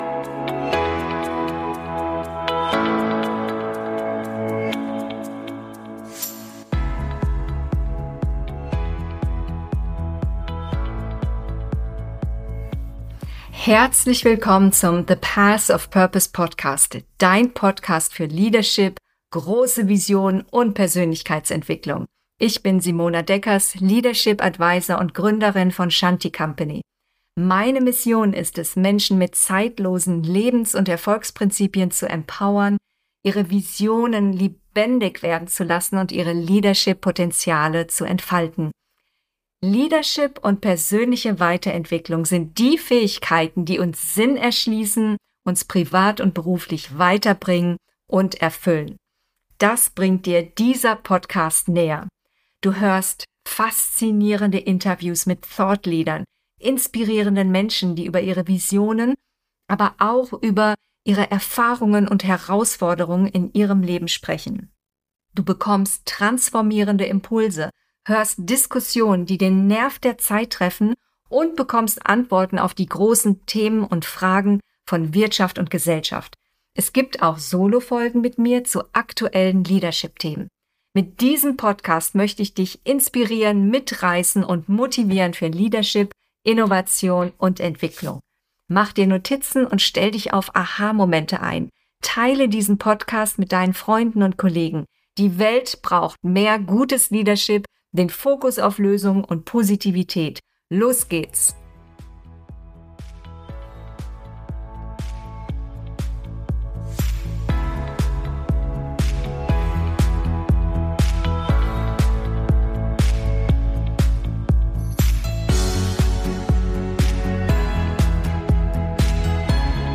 Mentale Gesundheit am Arbeitsplatz: was Unternehmen und Mitarbeitende tun können - Interview